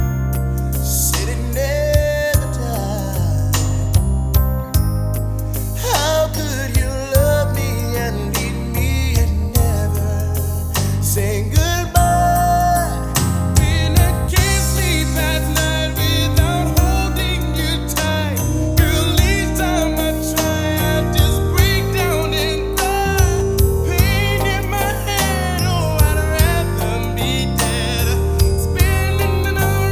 • R&B/Soul
American R&B group
E-flat major
time signature of 6/8 with a tempo of 150 beats per minute.